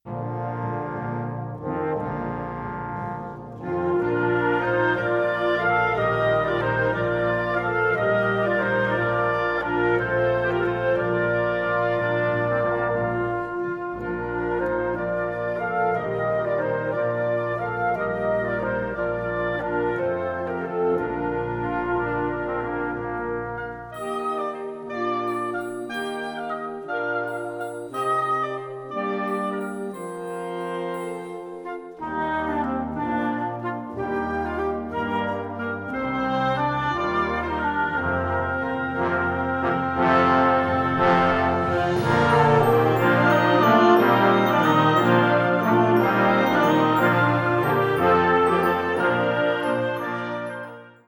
Kategorie Blasorchester/HaFaBra
Unterkategorie Konzertmusik